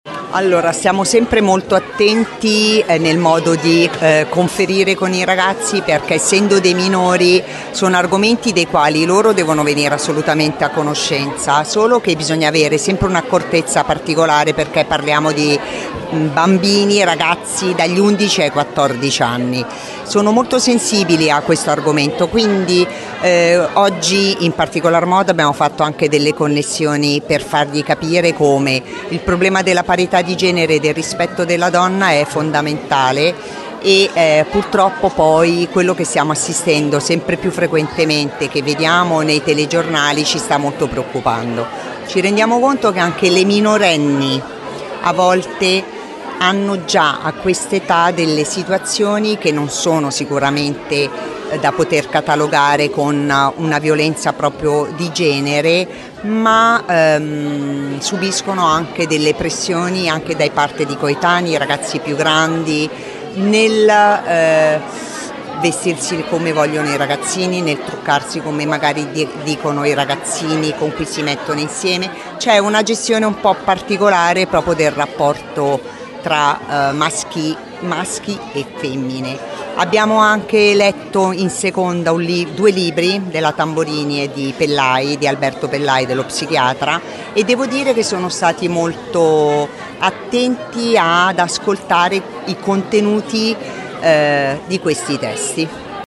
LATINA –  Si sono alternati  momenti dedicati alle  premiazioni del concorso Educare al rispetto e momenti di approfondimento sul tema della prevenzione della violenza di genere, nell’evento tematico promosso dal Comune di Latina per il 25 novembre che si è svolto al Teatro Ponchielli di Latina.